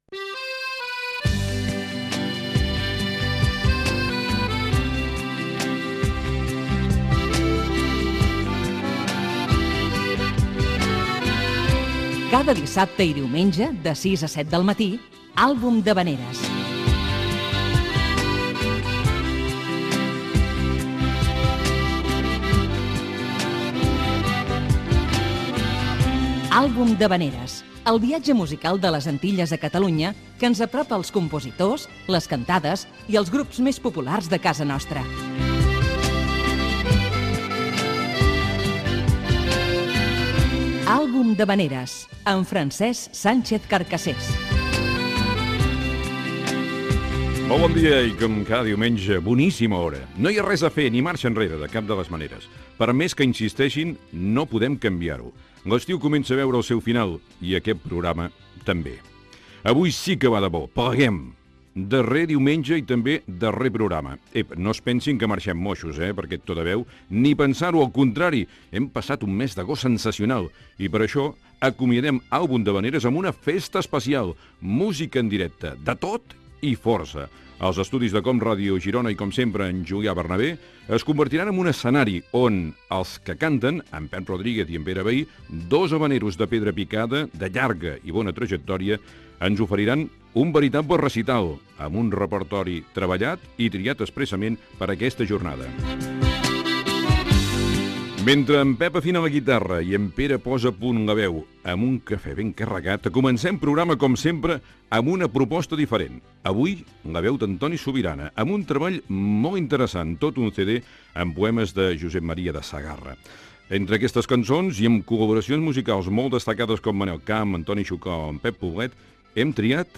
Careta, inici del darrer programa de la temporada d'estiu i tema musical.
Musical